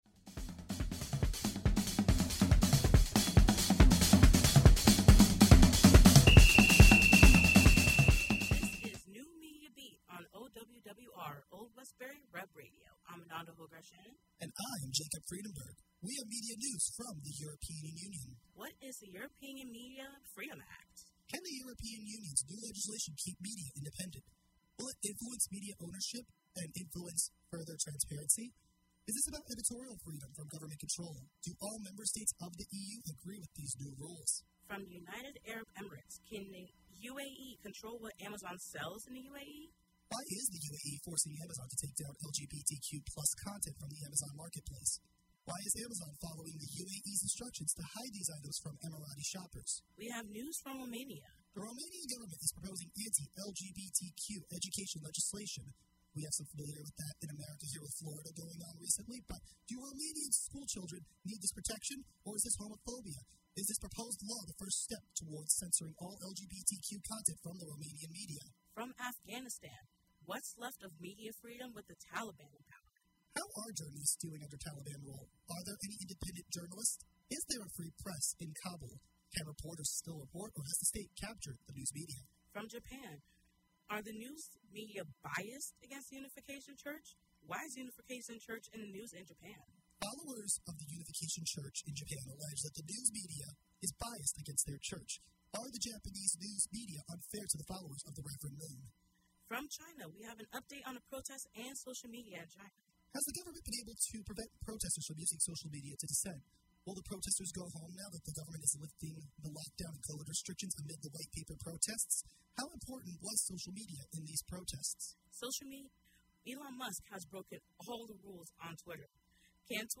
The NMB Podcast streams live on Old Westbury Web Radio Thursdays from 10:00-11:00 AM EST. Can’t listen live?